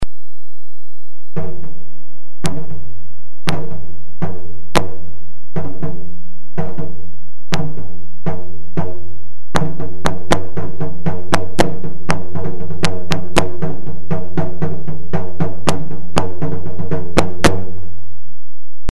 RENAISSANCE LONG DRUM
Long Drum Sound Clips